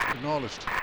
Alright, I made two English infrantry sounds.
1st time I made a sound effect. :o